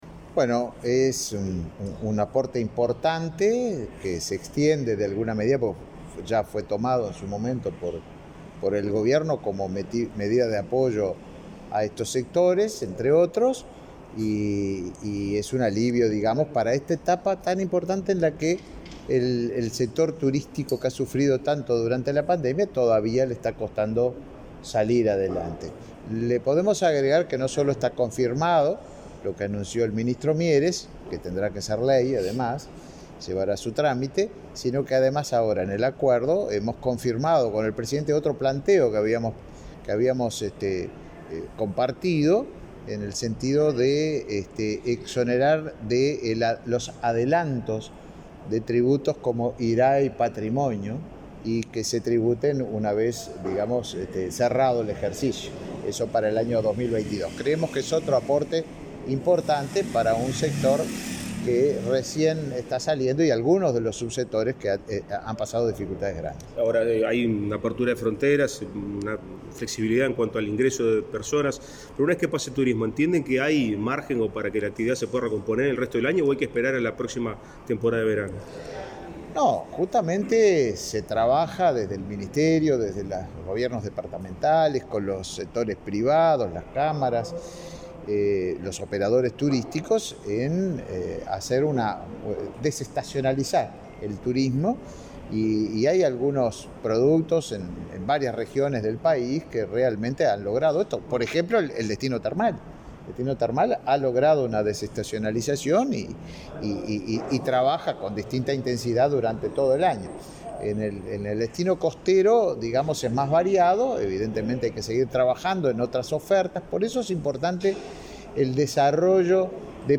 Declaraciones a la prensa del ministro de Turismo, Tabaré Viera